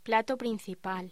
Locución: Plato principal